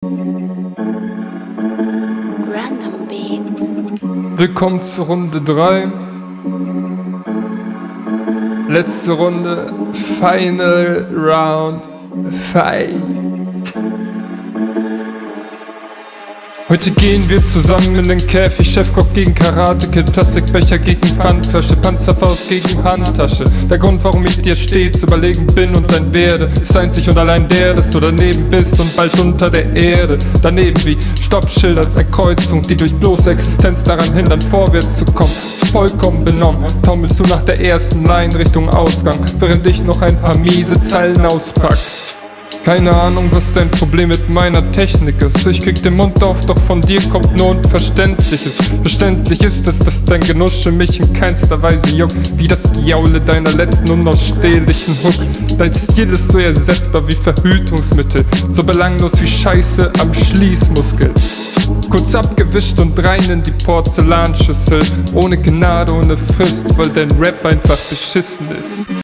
Intro ist wieder nicht geil. Aber Beat ist cool.